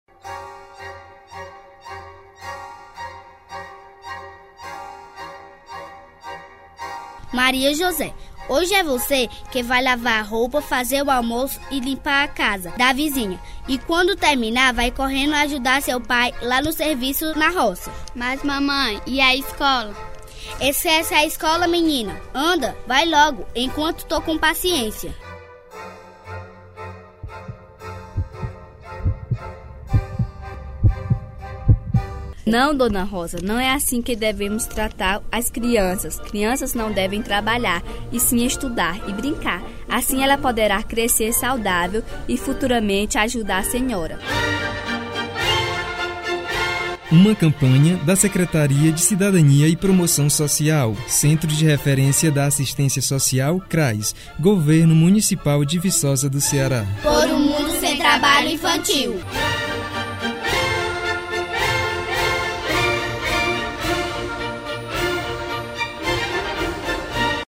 spots
spot - trabalho infantil .mp3